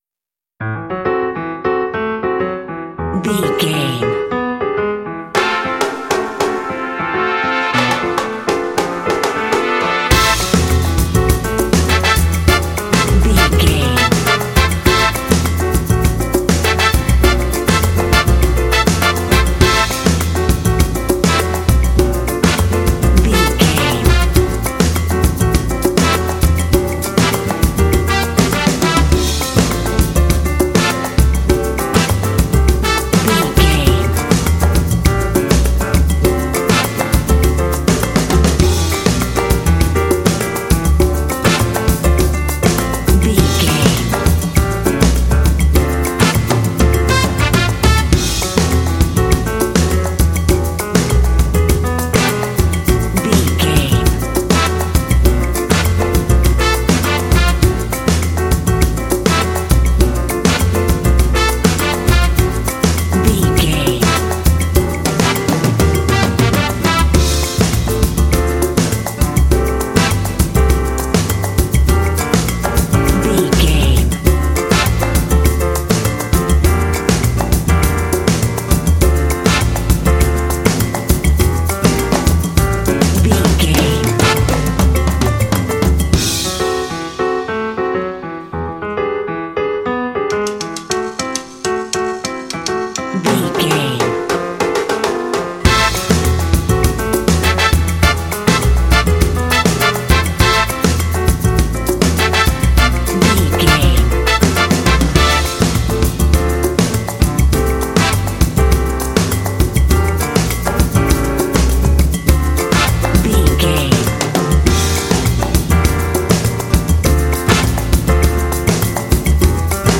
Aeolian/Minor
fun
energetic
bright
lively
horns
brass
piano
electric piano
percussion
80s
latin